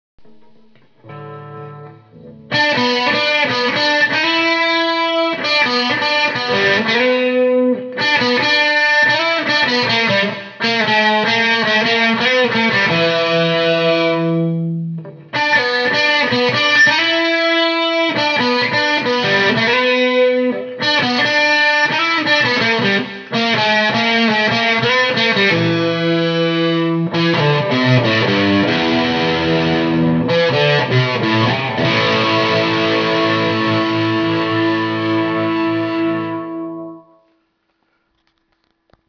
Explorer headless style with EMG 81/85 pickups and 9/18v switching satin mahogany finish
Sound is loud but harsh.